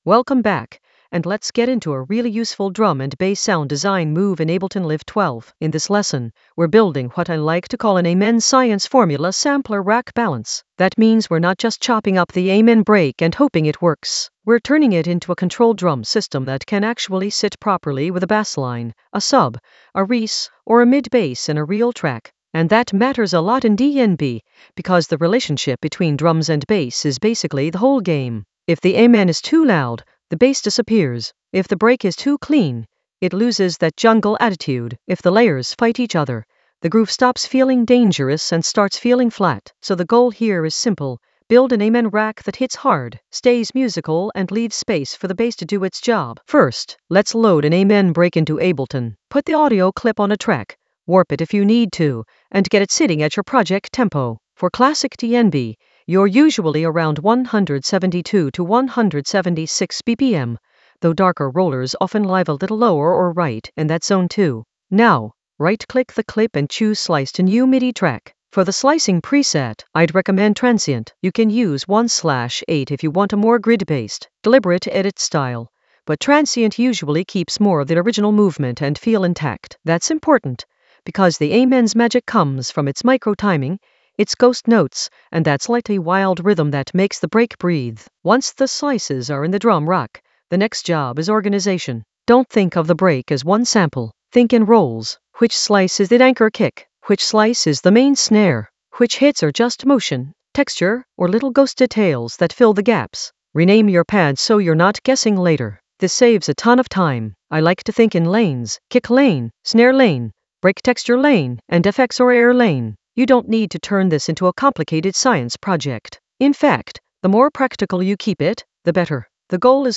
An AI-generated intermediate Ableton lesson focused on Amen Science formula: sampler rack balance in Ableton Live 12 in the Sound Design area of drum and bass production.
Narrated lesson audio
The voice track includes the tutorial plus extra teacher commentary.